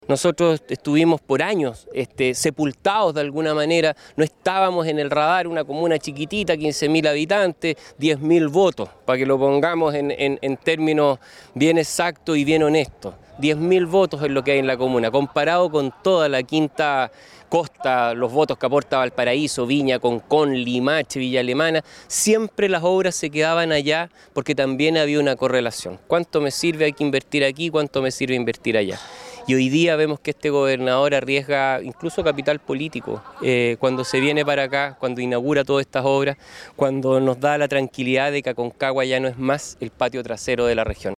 Por su parte el Alcalde de Catemu, Rodrigo Díaz, agradeció que ahora llegan las inversiones, pues antes «no estábamos en el radar.»
Alcalde-Catemu.mp3